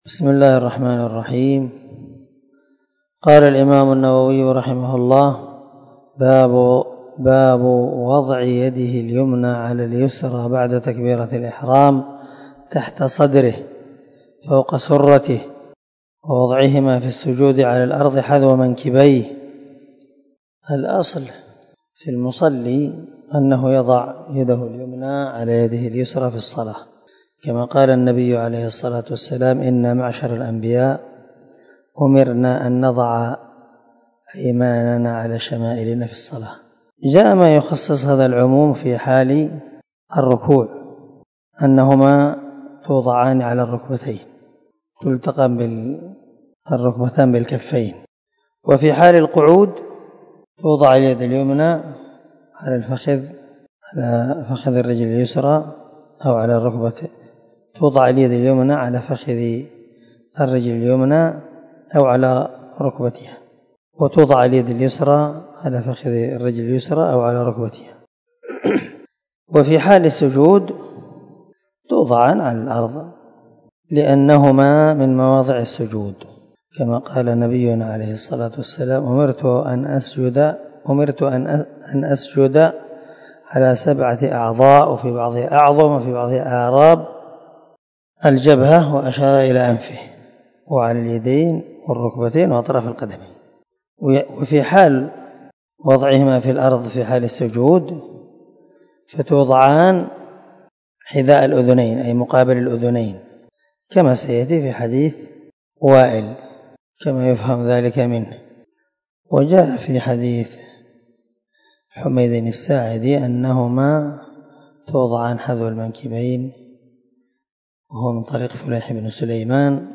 277الدرس 21 من شرح كتاب الصلاة حديث رقم ( 401 ) من صحيح مسلم